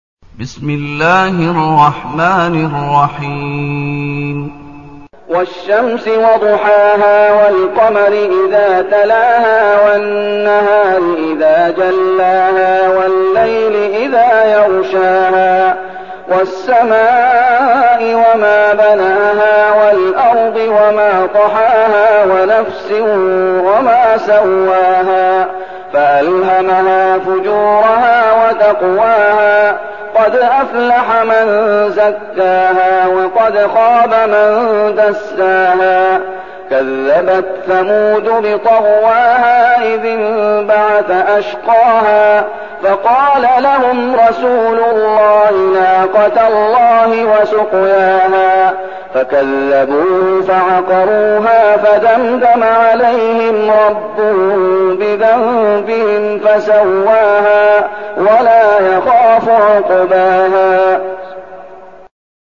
المكان: المسجد النبوي الشيخ: فضيلة الشيخ محمد أيوب فضيلة الشيخ محمد أيوب الشمس